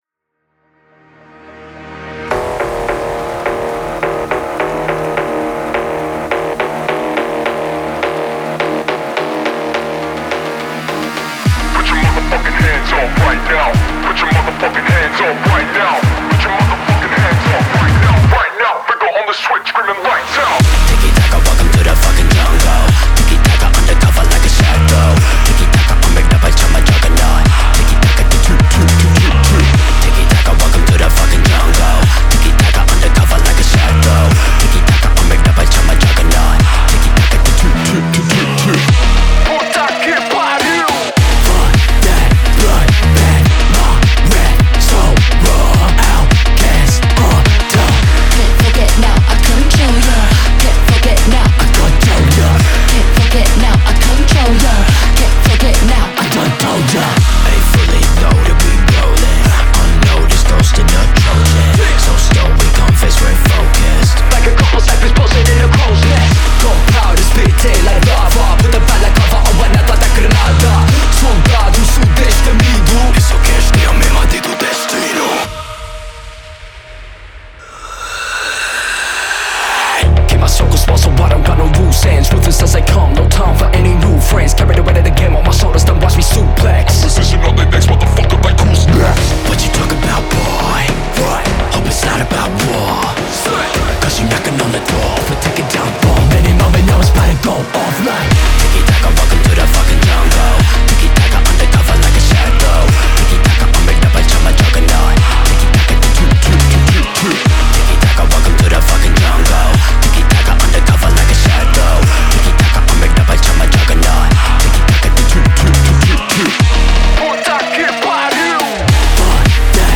BPM105-105
Audio QualityPerfect (High Quality)
Phonk song for StepMania, ITGmania, Project Outfox
Full Length Song (not arcade length cut)